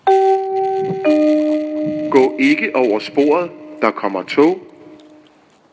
Jernbaneovergange
Ølsted St.